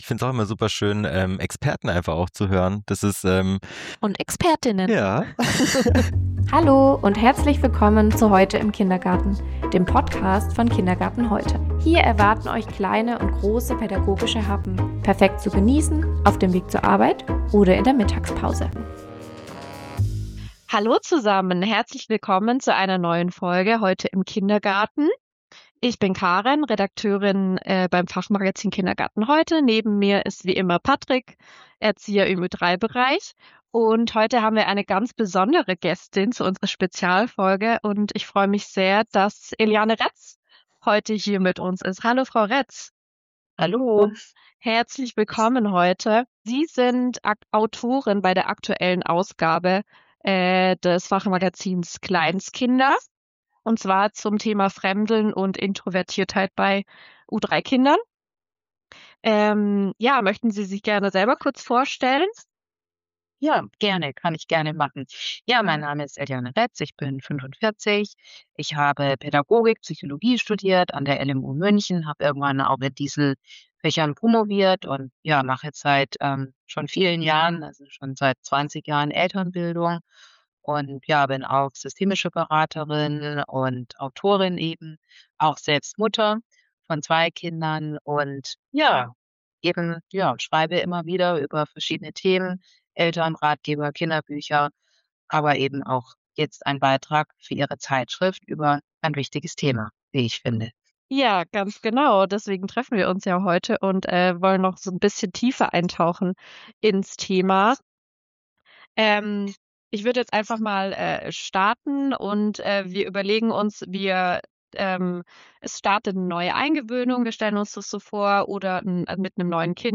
im Interview: Fremdeln bei Kleinstkindern? Ganz normal!